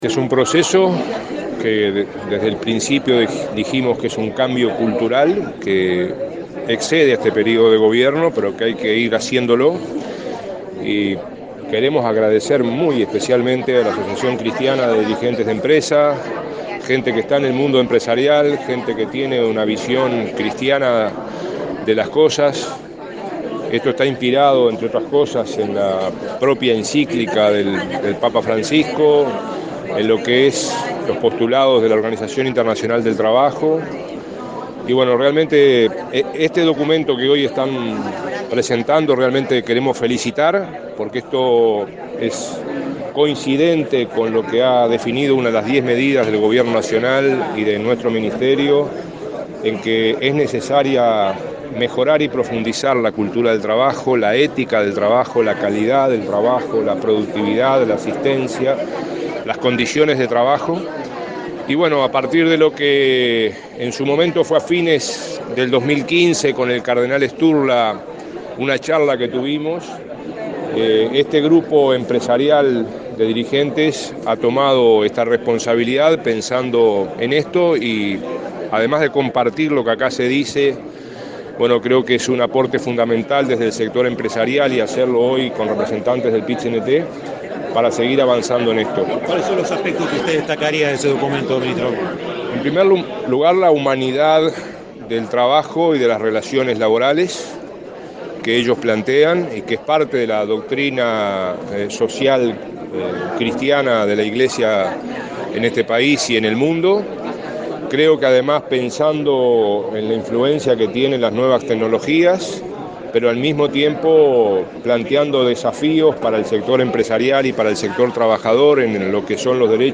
El ministro de Trabajo, Ernesto Murro, destacó este jueves en un seminario de Acde, que se está dando un proceso vinculado a la cultura de trabajo como la acreditación de saberes, la culminación de estudios por parte de trabajadores y empresarios, la creación de aulas móviles con enseñanza de tecnología para trabajadores y la formación en turismo. Adelantó que se avanza en la formación dual, en el trabajo mientras se trabaja.